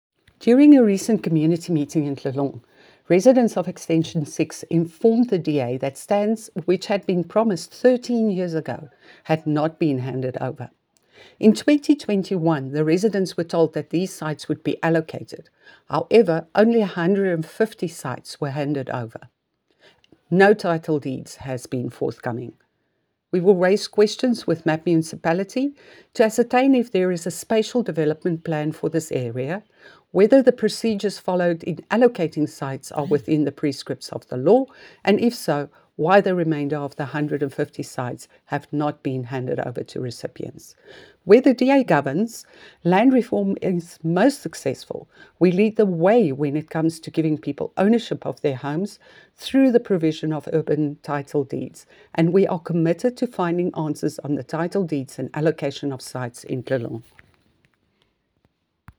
English and Afrikaans soundbites by Cllr Bea Campbell-Cloete and Sesotho by Cllr Moshe Lefuma.
Eng-voice.mp3